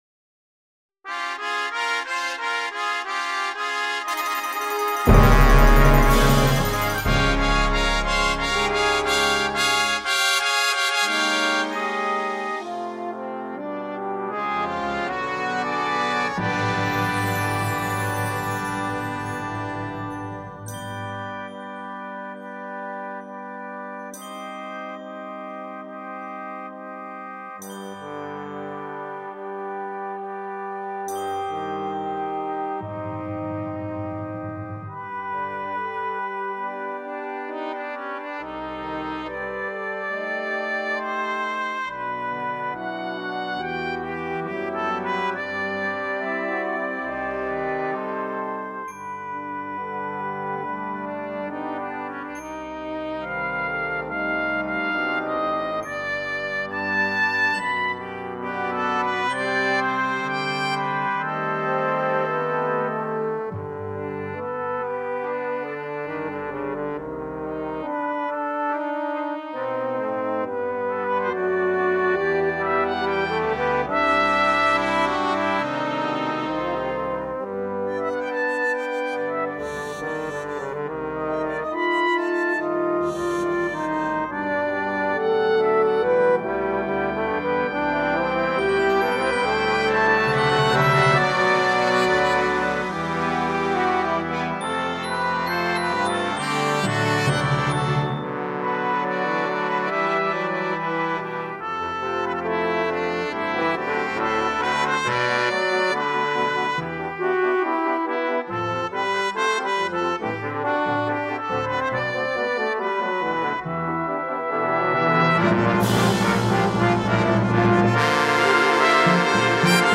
• Timpani